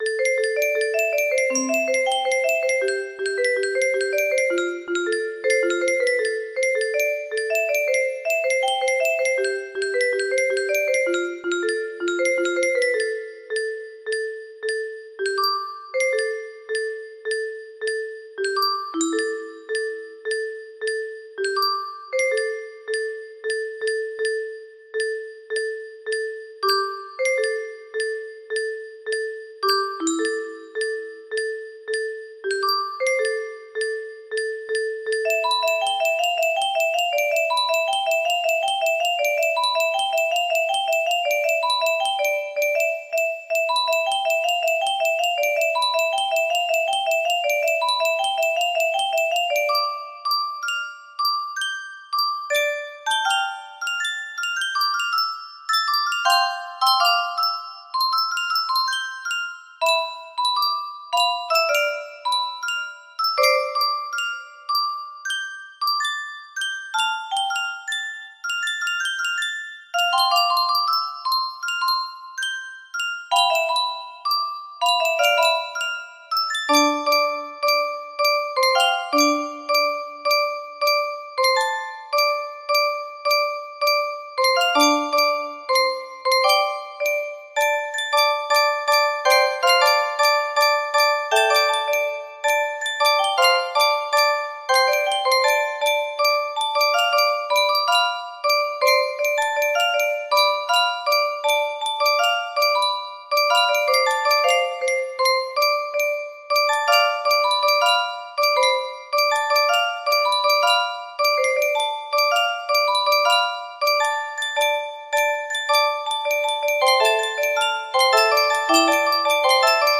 Grand Illusions 30 (F scale)
Arranged and Decorated Musicbox For GI 30 F Scale